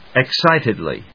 音節ex・cít・ed・ly 発音記号・読み方
/ɪˈksaɪtʌdli(米国英語), ɪˈksaɪtʌdli:(英国英語)/